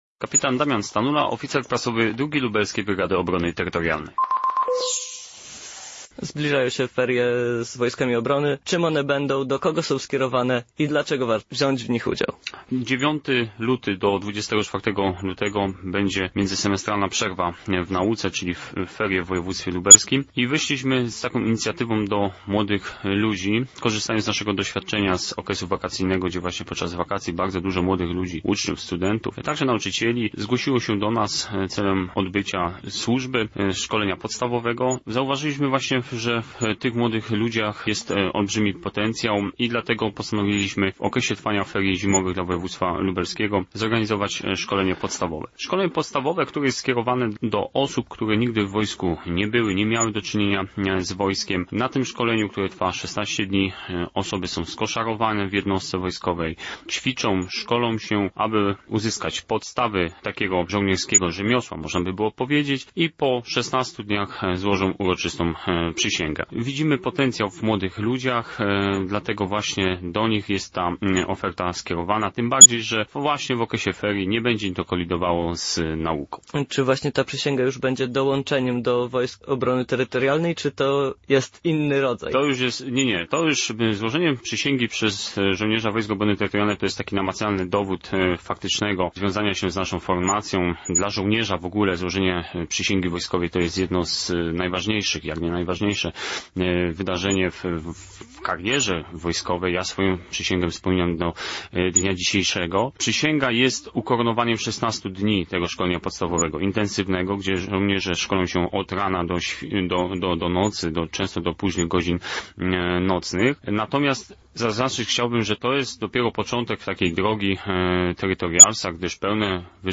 wywiadzie